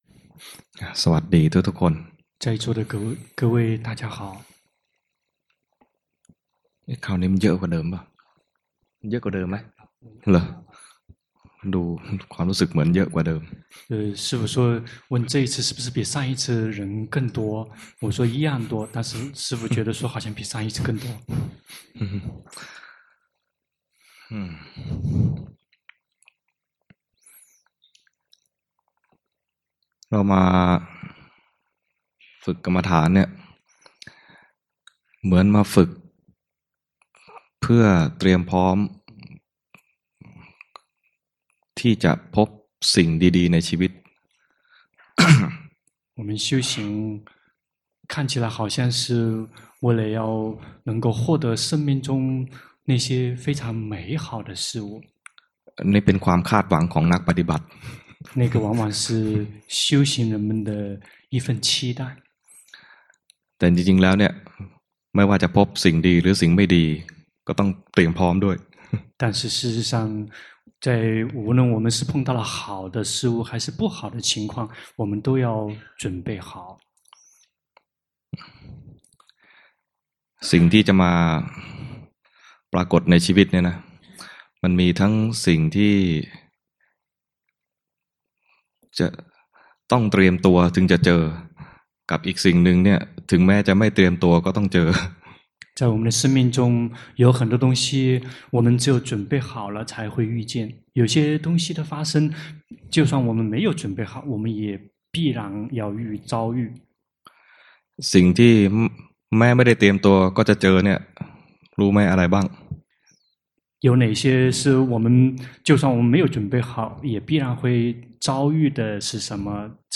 長篇法談｜好也行，不好也可以 - 靜慮林